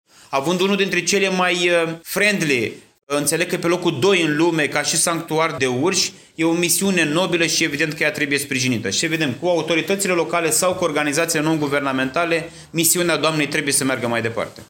Prezent la Braşov, într-o vizită în Parcul naţional Pitara Craiului, ministrul Mediului, Apelor şi Pădurilor, Costel Alexe, a dat asigurări că se va implica în susţinerea acestei asociaţii şi a Sanctuarului de Urşi de la Zărneşt: